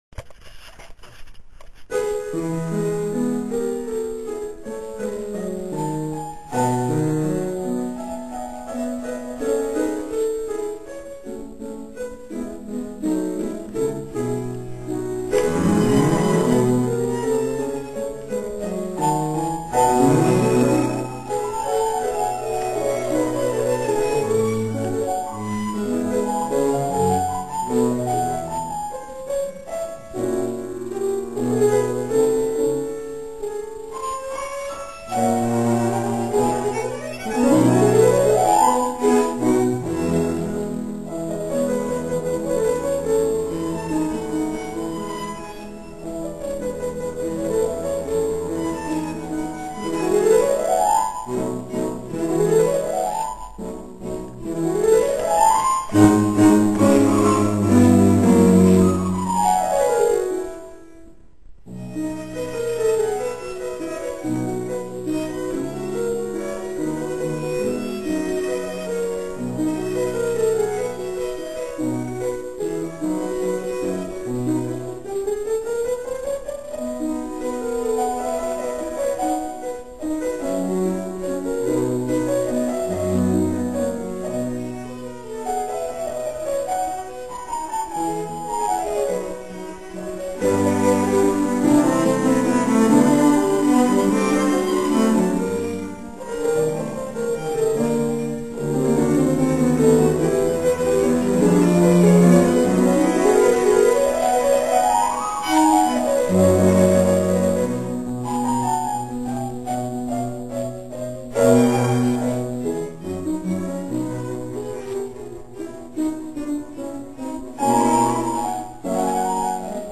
19. komorní koncert na radnici v Modřicích
- ukázkové amatérské nahrávky, v ročence CD Modřice 2006 doplněno: